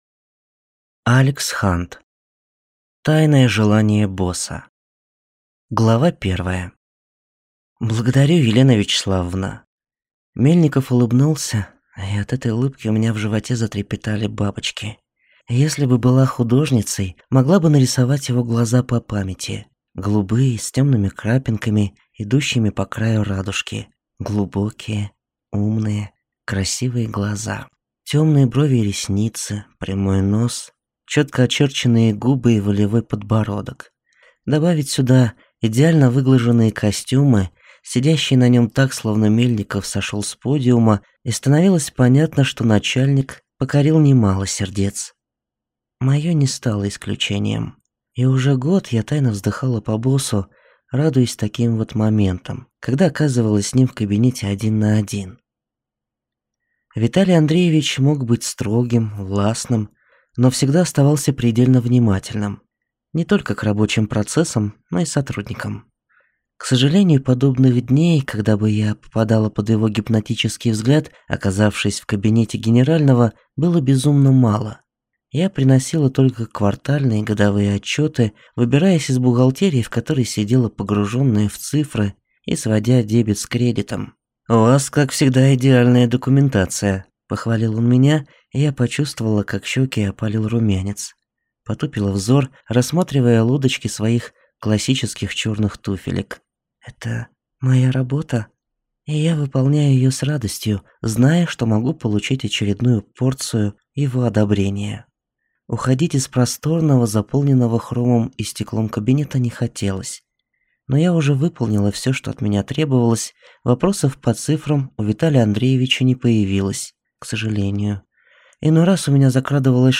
Аудиокнига Тайные желания босса | Библиотека аудиокниг